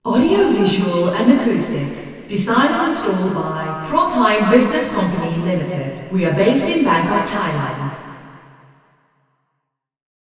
Not surprisingly, this placement produces some audible flutter echoes, but the improvement in SI is striking.
Receiver 04     STI = 0.58
RIR_MFA_W_A1_04_Female_Anechoic_Speech_MONO.wav